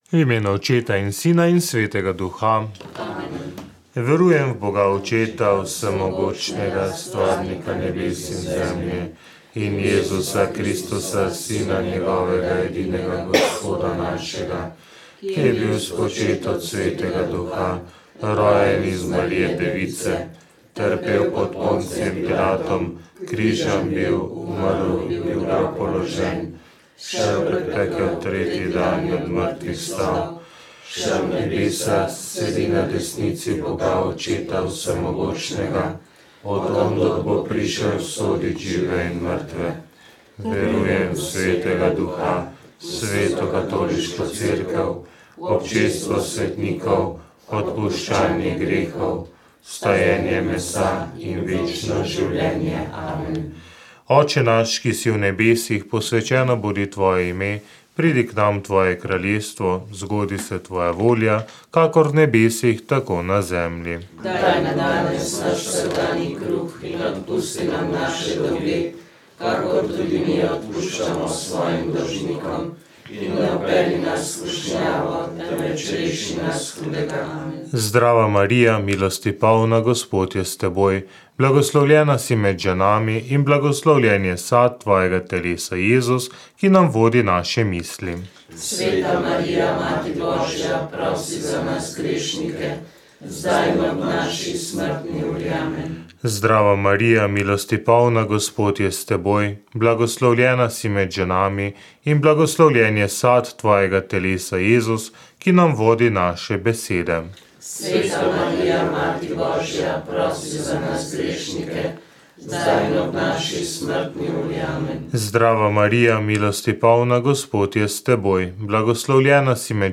Radio Ognjišče duhovnost Rožni venec VEČ ...